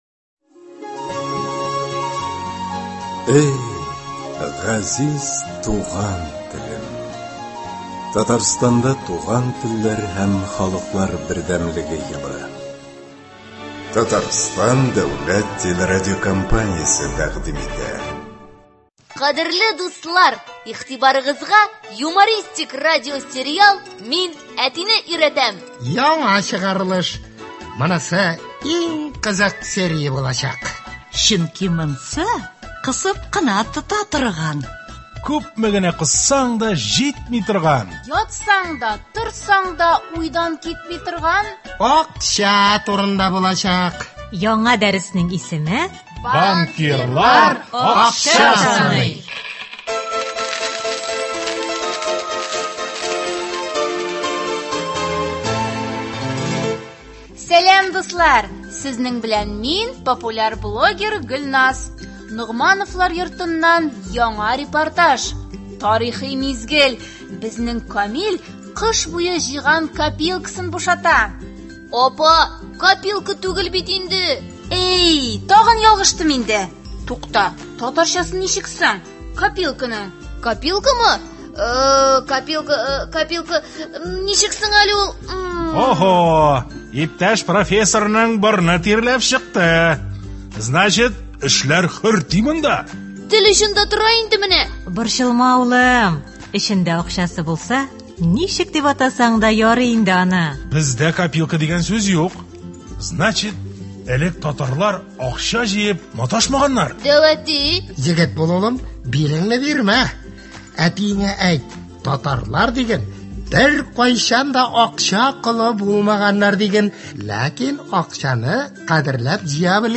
Ул – “Мин әтине өйрәтәм” дип исемләнгән радиосериал. Кыска метражлы әлеге радиоспектакльләрдә туган телебезне бозып сөйләшү көлке бер хәл итеп күрсәтелә һәм сөйләмебездәге хата-кимчелекләрдән арыну юллары бәян ителә.